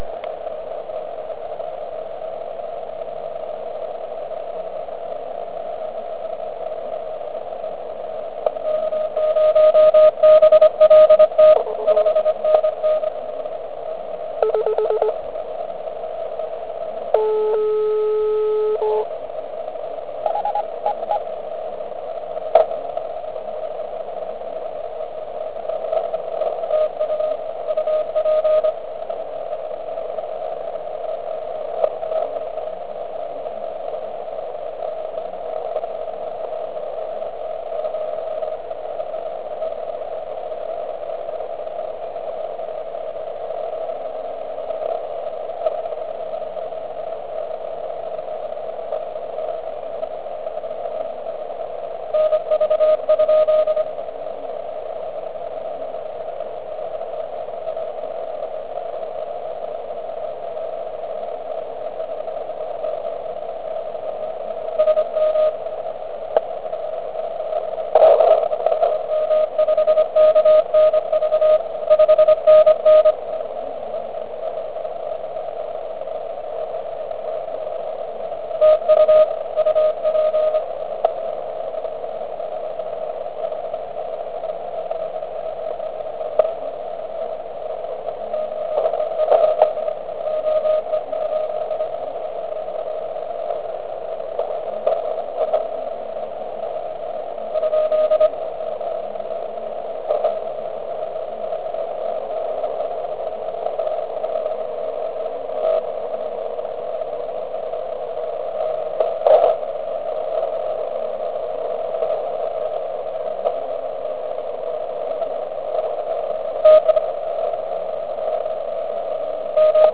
U ATS-3B je nastaven offset poněkud výše asi na 700Hz.
Určitě někomu bude vadit "zvonění" NF filtru.
DX stanici slyším velice pěkně.